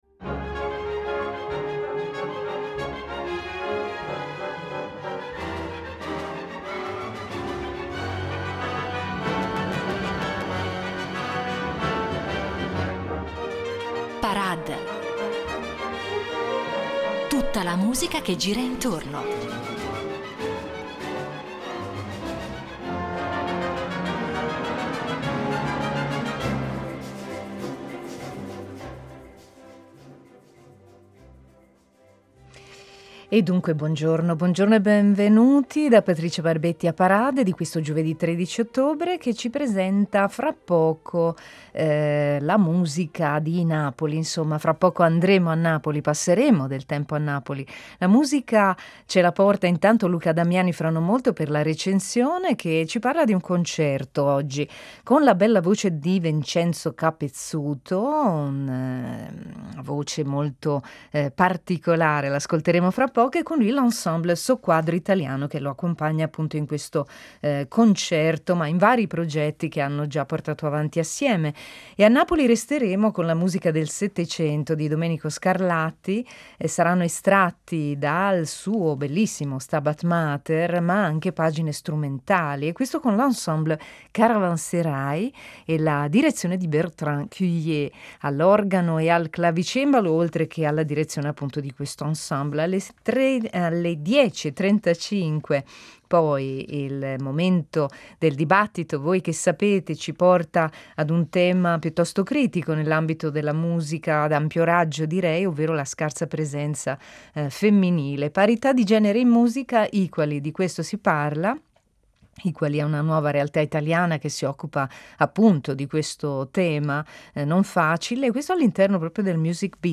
Recensione concerto